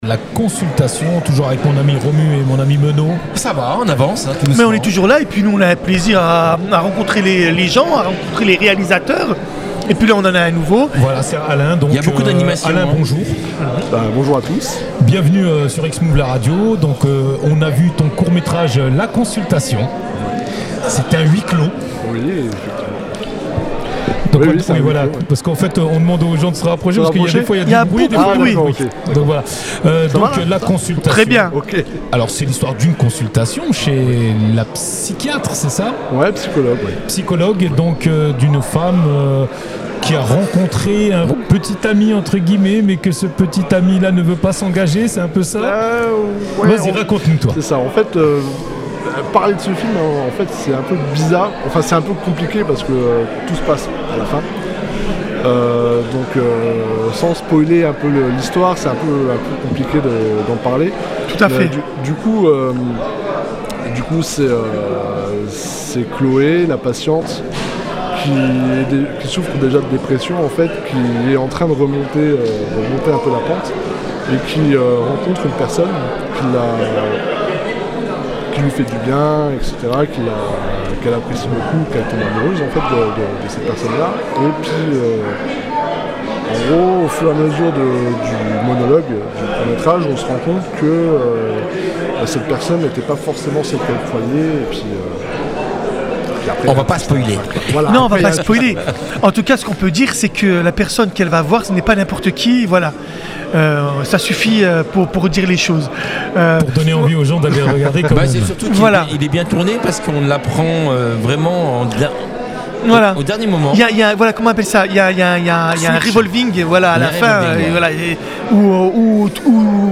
Boîte à images (Interviews 2025)